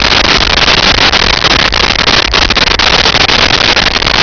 Sfx Amb Submarine Loop
sfx_amb_submarine_loop.wav